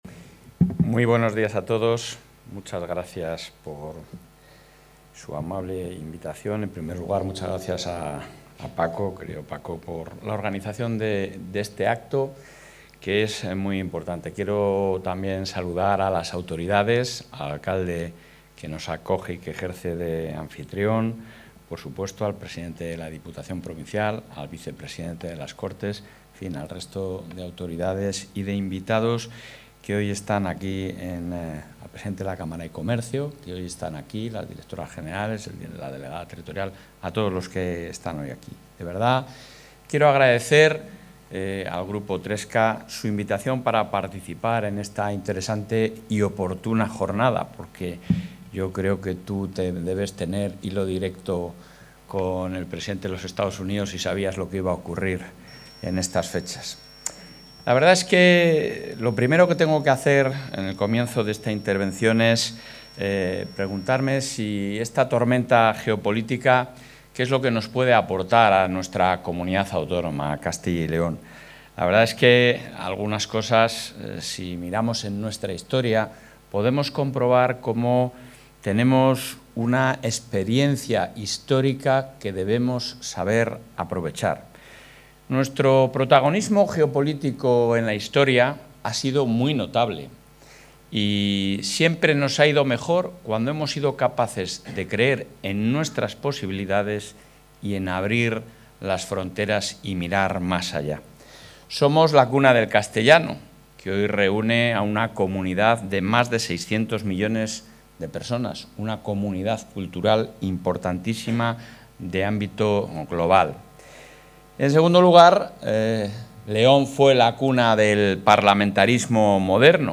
Intervención del presidente.
El presidente de la Junta de Castilla y León, Alfonso Fernández Mañueco, ha participado este jueves en la clausura del foro ‘España en la tormenta geopolítica: industria y poder en la era Trump’, organizado por TRESCA Ingeniería en Valladolid.